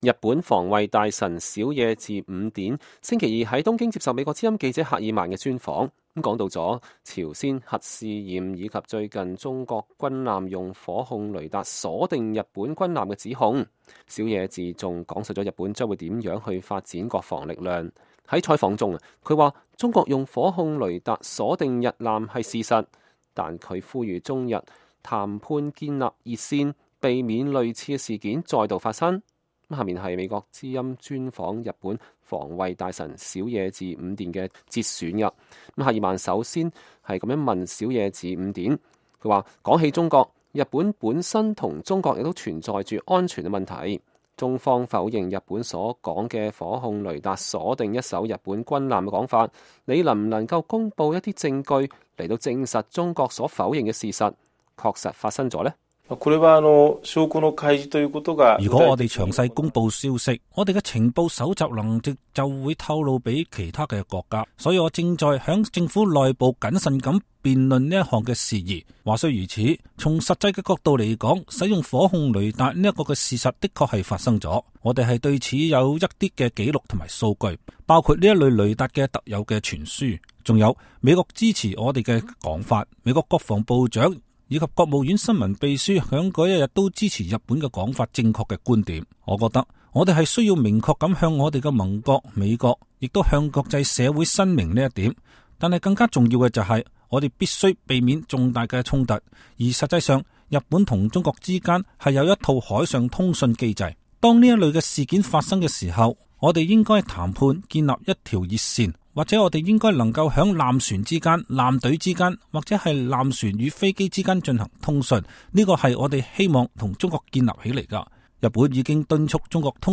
美國之音專訪日本防衛大臣